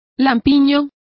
Complete with pronunciation of the translation of beardless.